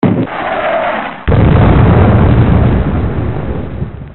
missle.mp3